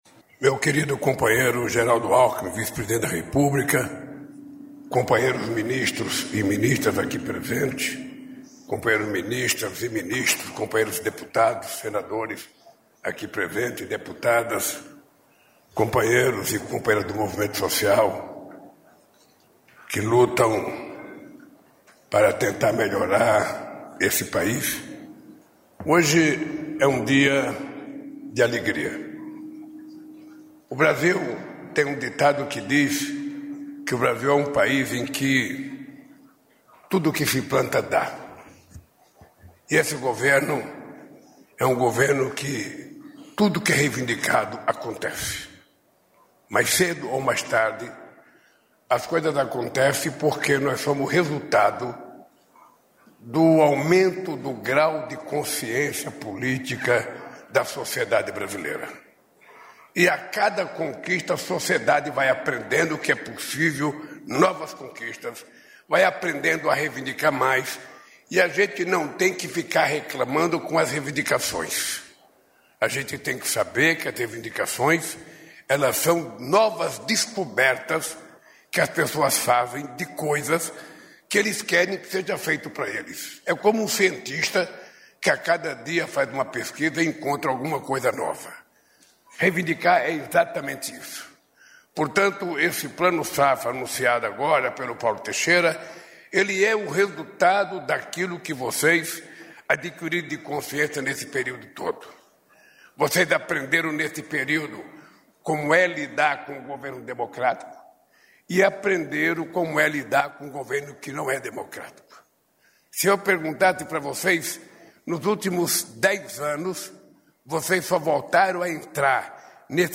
Íntegra do discurso do presidente Luiz Inácio Lula da Silva, na cerimônia de lançamento do Plano Safra da Agricultura Familiar 2025/2026, nesta segunda-feira (30), no Palácio do Planalto, em Brasília (DF).